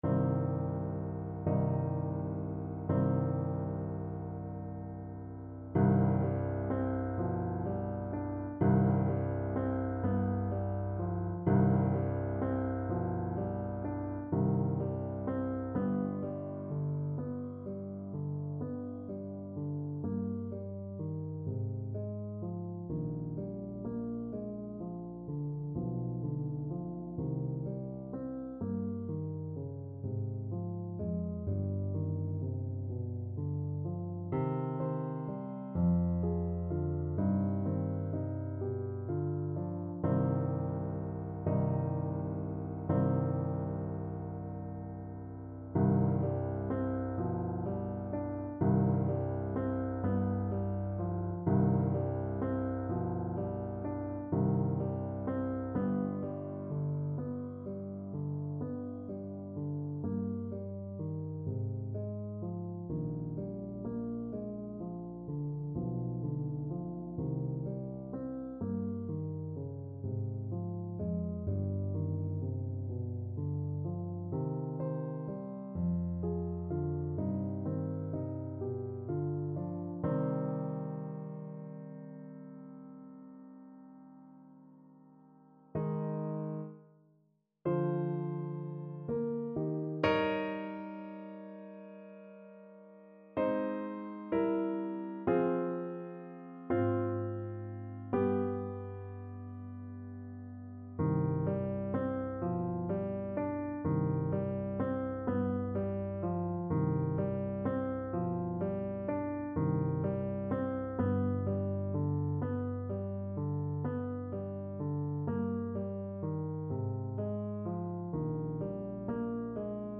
Play (or use space bar on your keyboard) Pause Music Playalong - Piano Accompaniment Playalong Band Accompaniment not yet available transpose reset tempo print settings full screen
C minor (Sounding Pitch) D minor (Trumpet in Bb) (View more C minor Music for Trumpet )
= 42 Andante con moto (View more music marked Andante con moto)
6/8 (View more 6/8 Music)
Classical (View more Classical Trumpet Music)